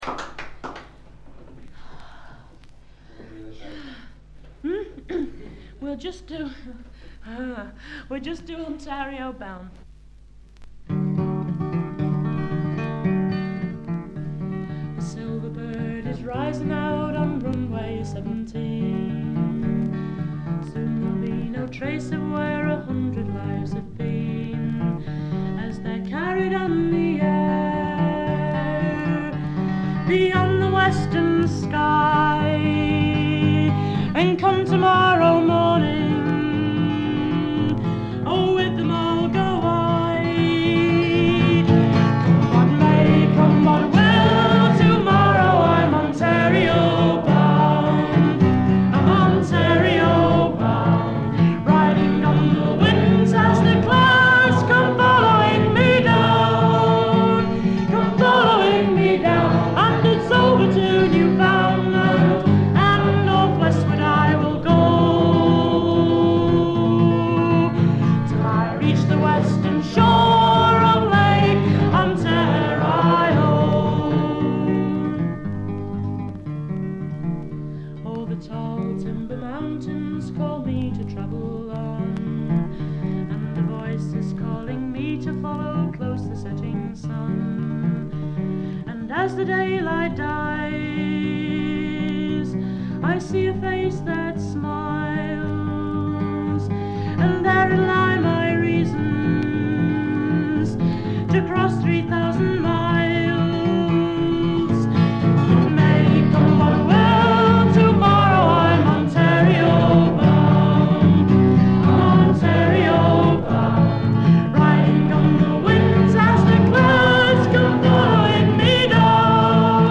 軽微なバックグラウンドノイズにチリプチ少々。
内容はギター弾き語りのライブで、全11曲のうち自作3曲、カヴァー1曲、残りがトラッドという構成です。
試聴曲は現品からの取り込み音源です。